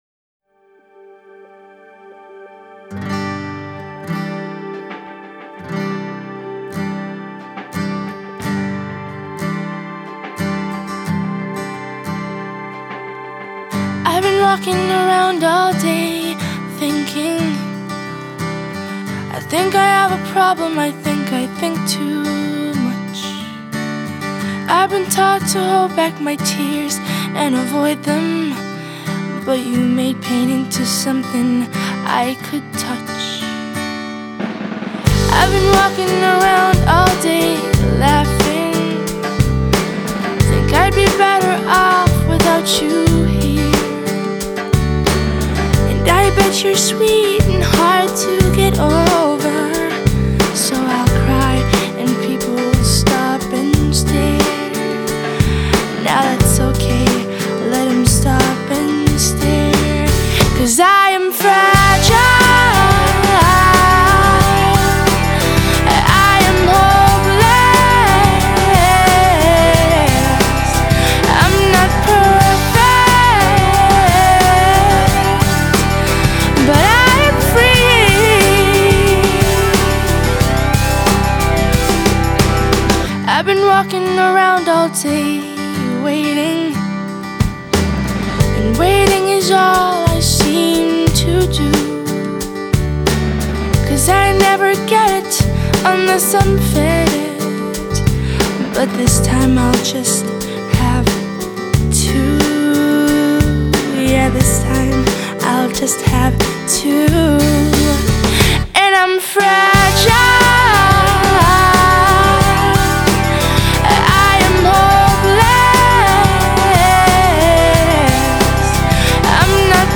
장르: Rock
스타일: Acoustic, Pop Rock